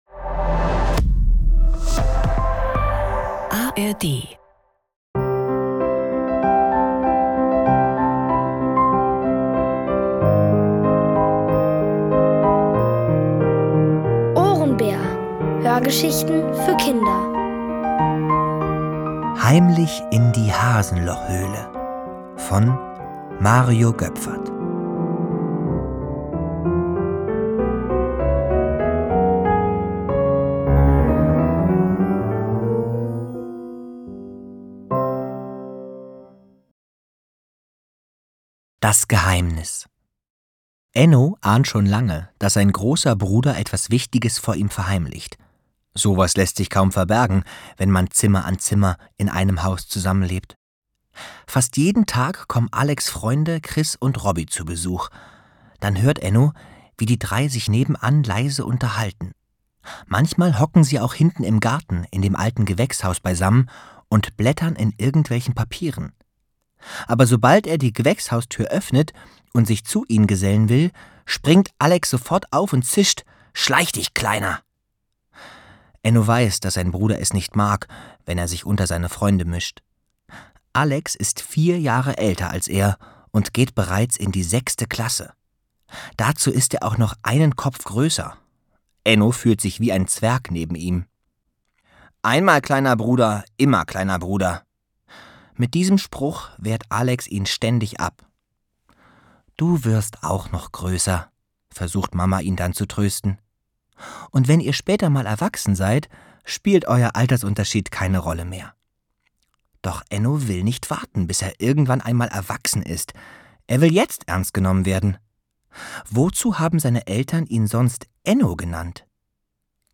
Heimlich in die Hasenlochhöhle | Die komplette Hörgeschichte! ~ Ohrenbär Podcast